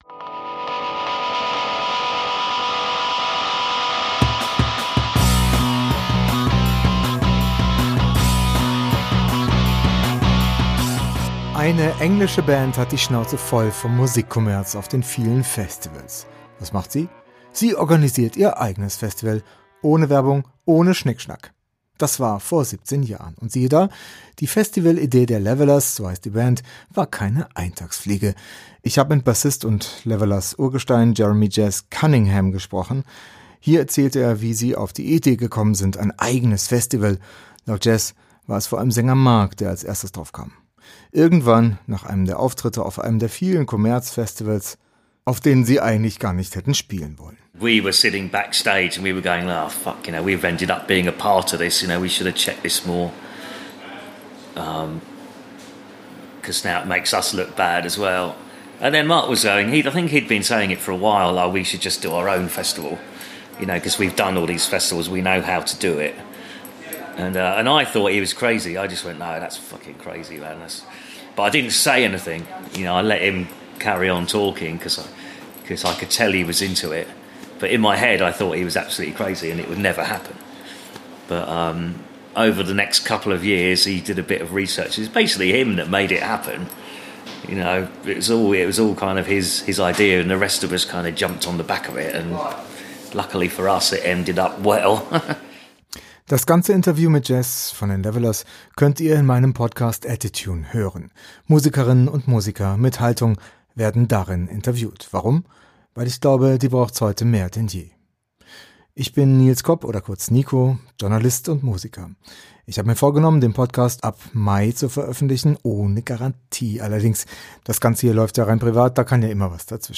Hier ein Ausschnitt aus der Folge, in der Ihr mein Interview mit
dem Levellers-Bassisten und Künstler Jeremy Cunningham hören könnt.